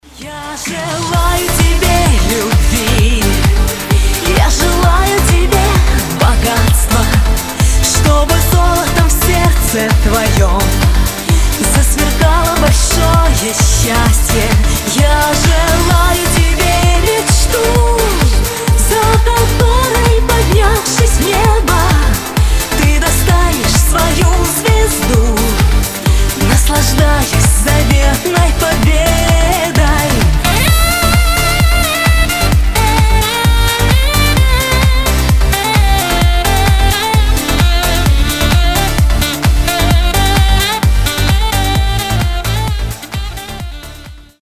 Саксофон , Танцевальные рингтоны , Веселые рингтоны